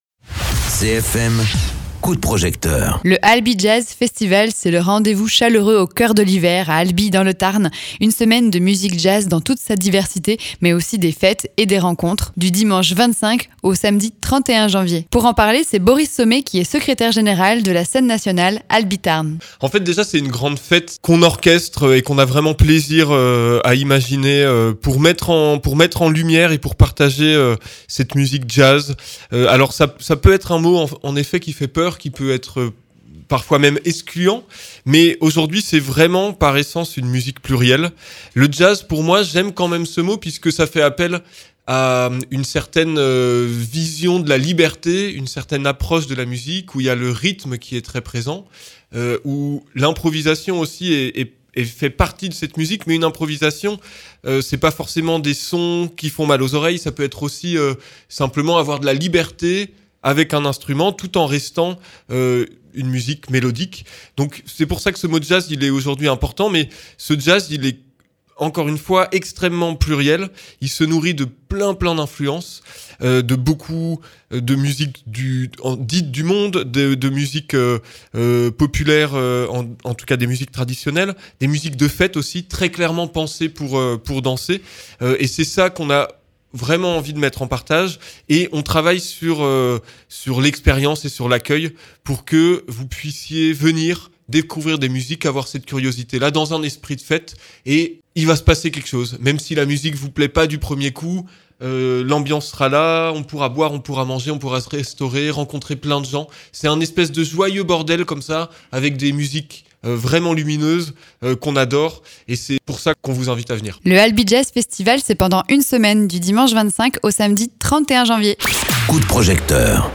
Présenté par